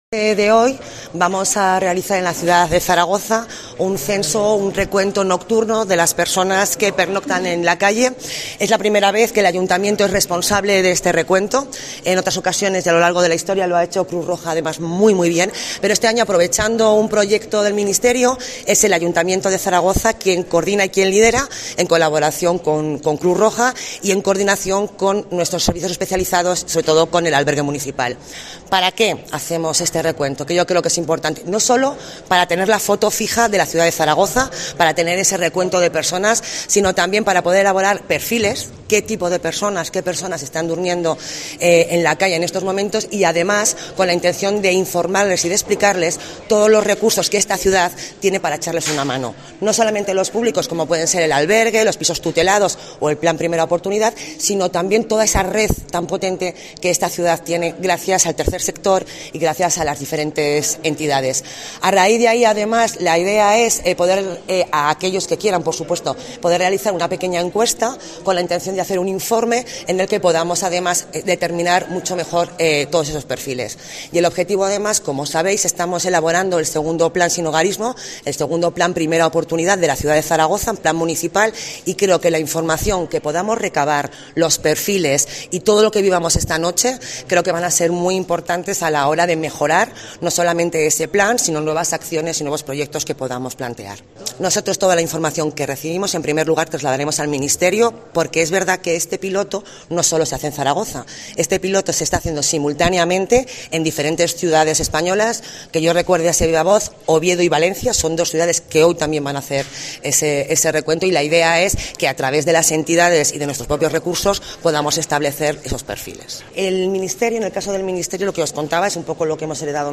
La consejera de Políticas Sociales Marián Orós, sobre el recuento de personas sin hogar de Zaragoza.